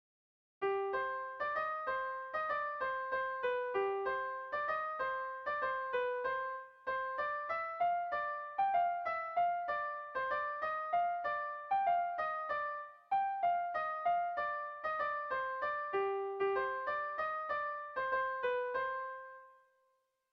Bertso melodies - View details   To know more about this section
Irrizkoa
Seiko handia (hg) / Hiru puntuko handia (ip)
ABD